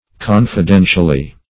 Confidentially \Con`fi*den"tial*ly\, adv.